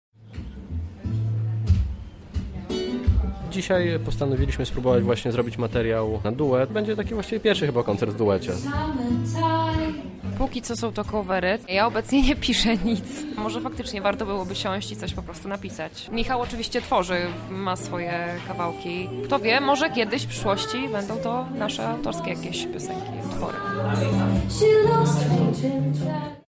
Bossa Nova, jazz i swing w Akwareli
Soulowe dźwięki zabrzmiały w przytulnej kawiarni.
gitarze
Duet zagrał utwory w klimatach bossa novy, R’n’b i swingu.
koncert